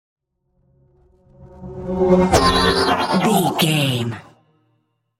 Pass by fast vehicle sci fi
Sound Effects
futuristic
pass by
vehicle